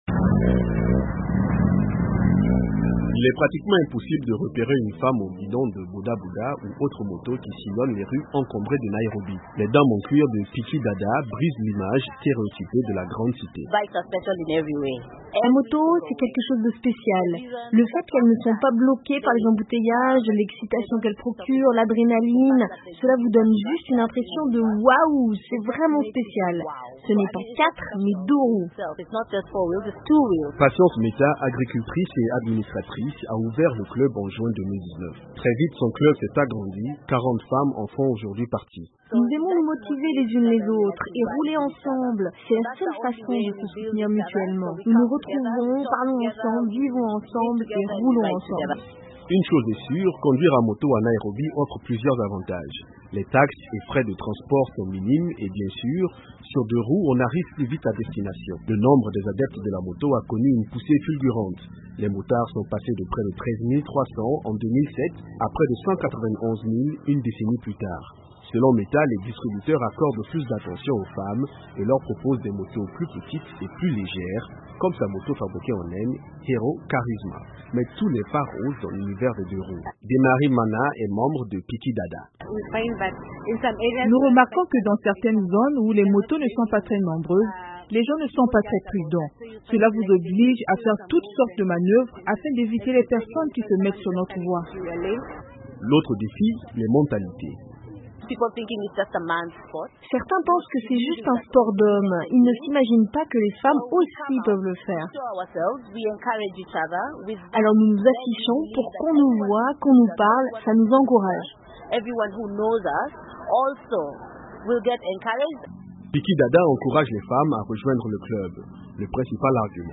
La capitale du Kenyan, Nairobi enregistre un nouveau club de motos composé de femmes. L’objectif n’est pas seulement de développer leurs capacités à vaincre la circulation souvent infernale, c’est aussi un moyen pour elles de défier les stéréotypes. Un reportage